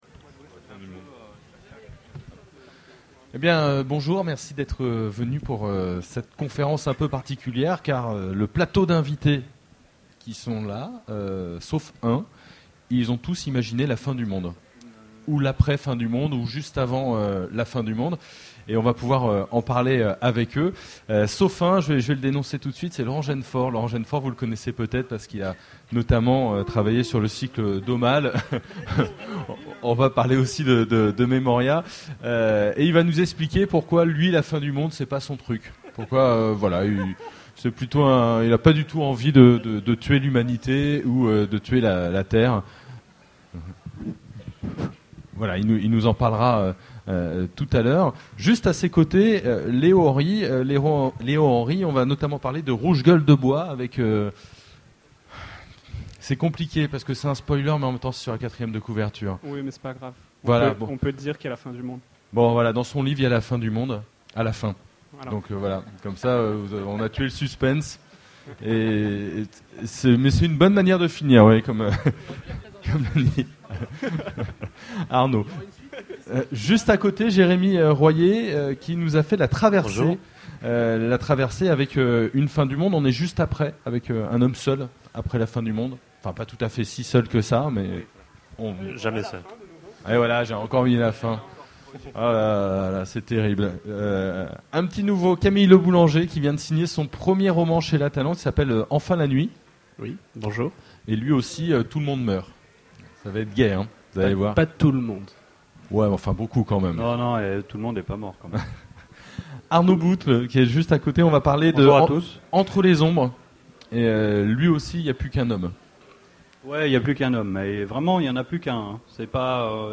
Utopiales 2011 : Conférence Les histoires de fin du monde